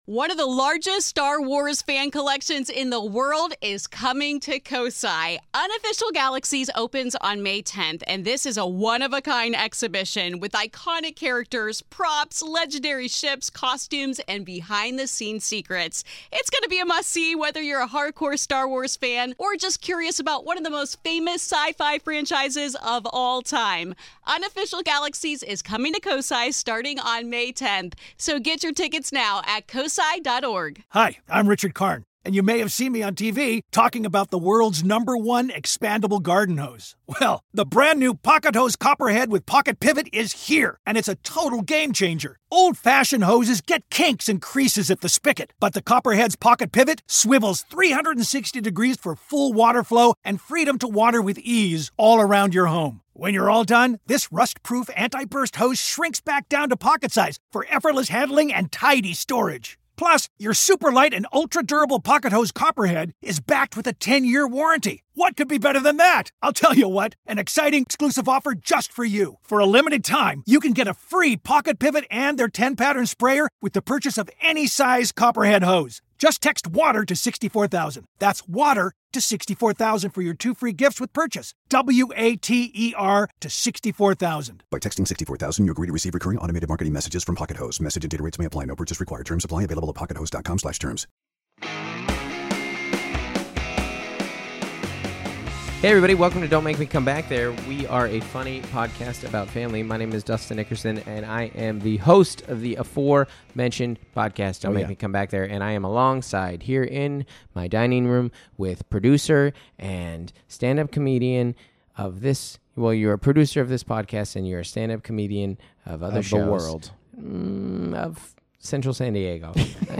Actor and Last Comic Standing finalist, Jeff Dye, sits down to talk about being nice while driving, how to be a cool youth group leader and the joy of connecting with a child over a mutual love of 'A Bug's Life'.